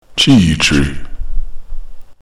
chee-tree